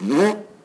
Explo_2.wav